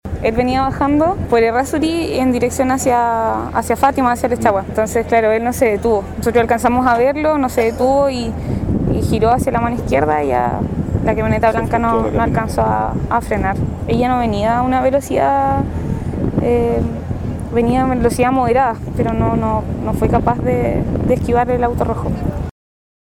La misma joven señaló que el automóvil rojo iba por calle Errázuriz para doblar por Costanera, sin embargo al parecer no se detuvo según lo indicaba la señalización.